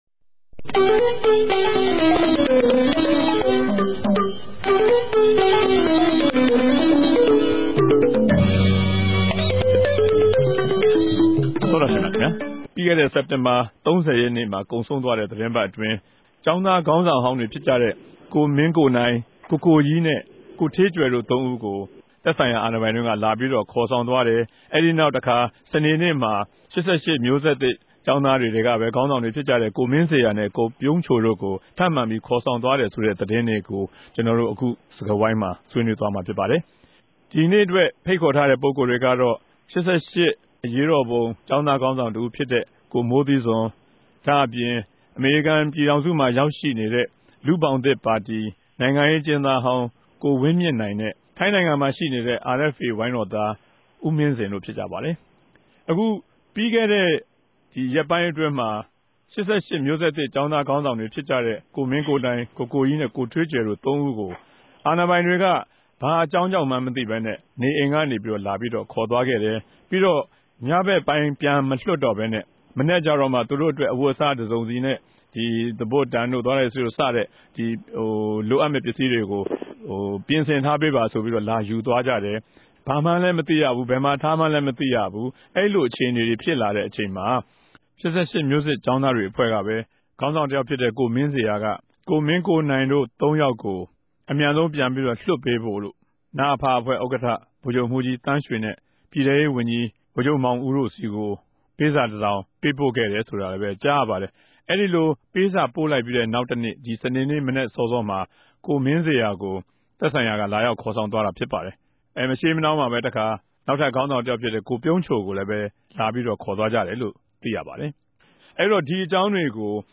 ဝၝရြင်တန်္ဘမိြႚတော် RFAစတူဒီယိုထဲက